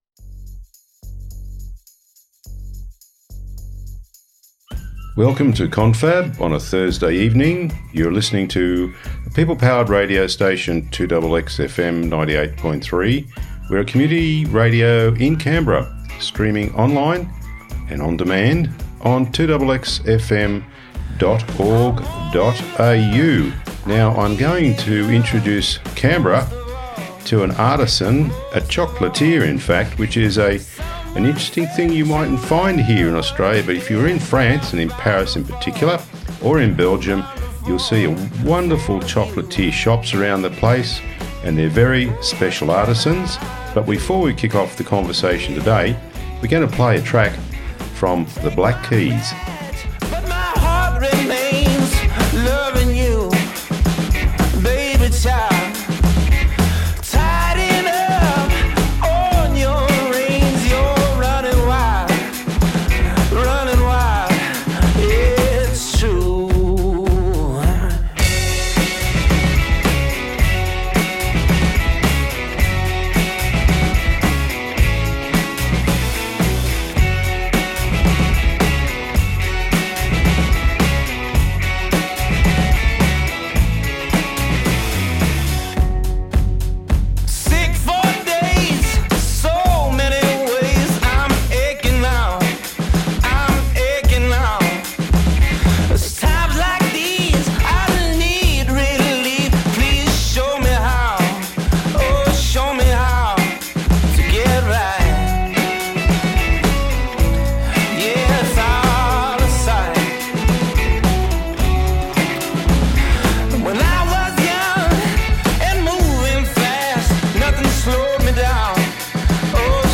The Confab – a program of conversations with people from Canberra and from around the world.